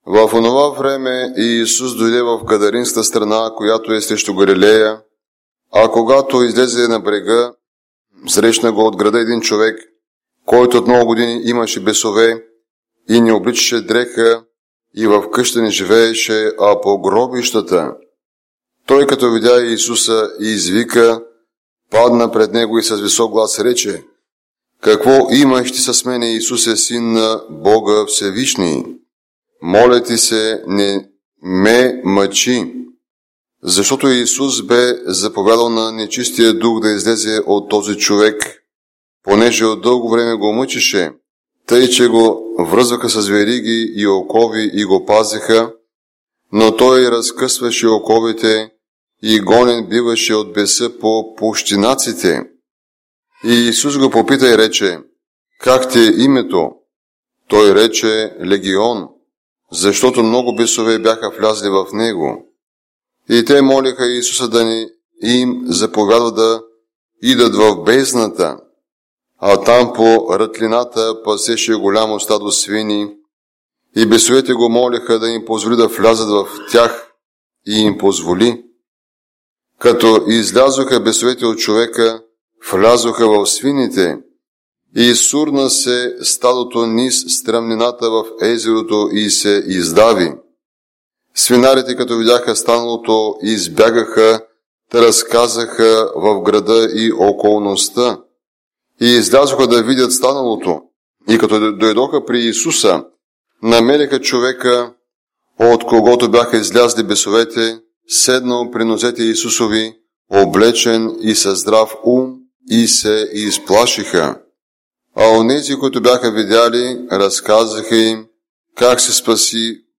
Евангелско четиво